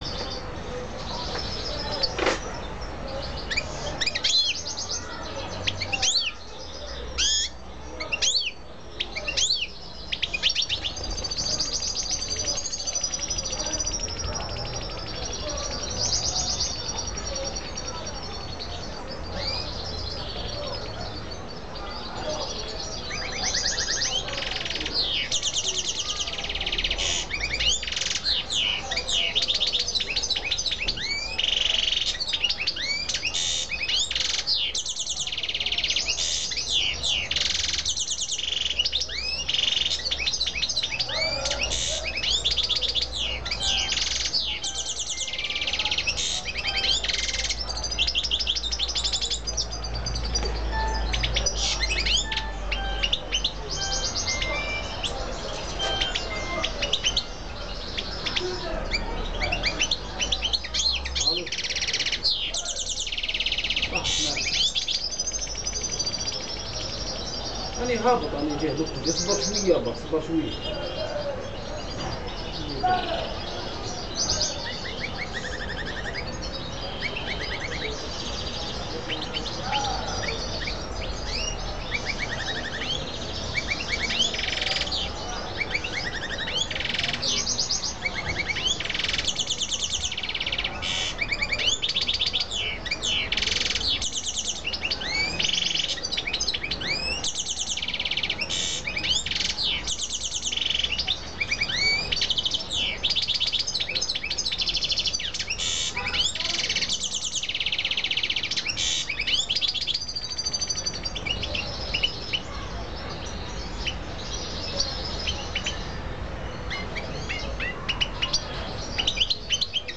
جلوه های صوتی
دانلود صدای قناری ماده اماده و صدای جفت گیری آن ها از ساعد نیوز با لینک مستقیم و کیفیت بالا